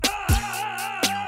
ghbot - Discord version of greenhambot -- currently just plays meme sfx in voice channels + static text commands
ahhh.mp3